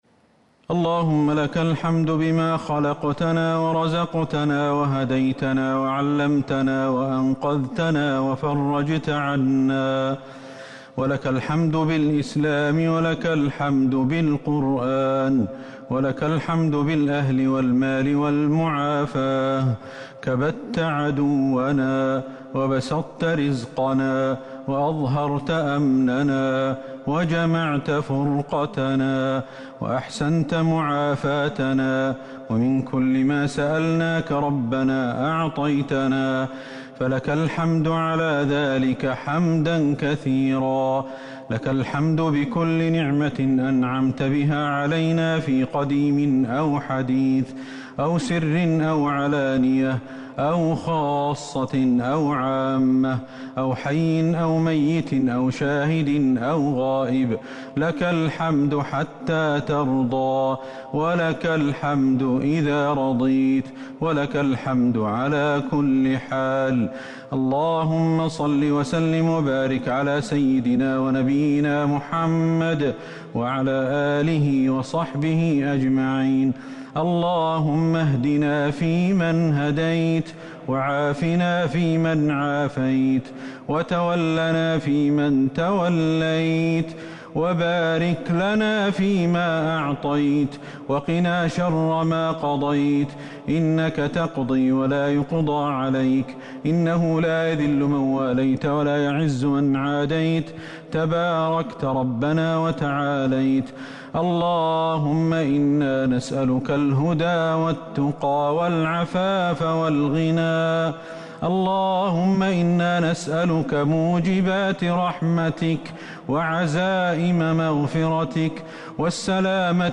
دعاء القنوت ليلة 12 رمضان 1441هـ > تراويح الحرم النبوي عام 1441 🕌 > التراويح - تلاوات الحرمين